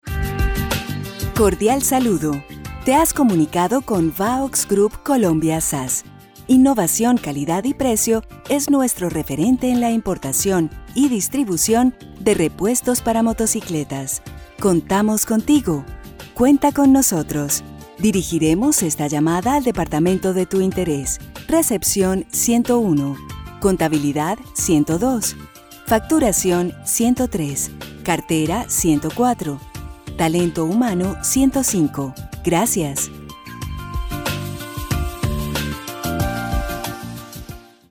Incluye grabación profesional, musicalización y asesoría en los textos.
Al comunicarse un cliente con una empresa, este es el primer contacto antes de ser atendido; por lo tanto, es ideal que le conteste una voz agradable, con buena pronunciación, elegante, con un buen tono y un fondo musical apropiado para la empresa.
Audios Telefónicos